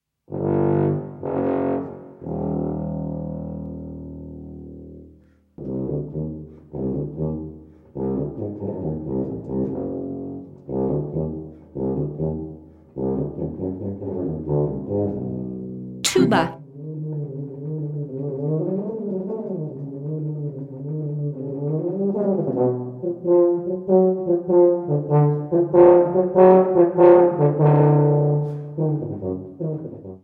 TUBA
Por último e non menos importante, o integrante cun rexistro máis grave da familia de vento metal, a tuba.
tuba.mp3